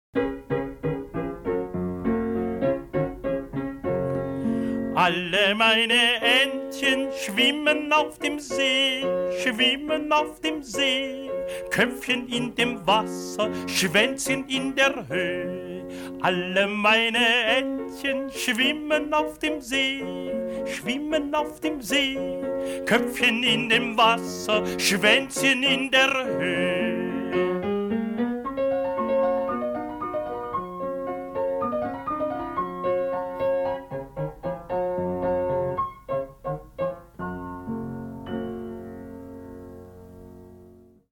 ist ein sehr bekanntes deutschsprachiges Kinderlied.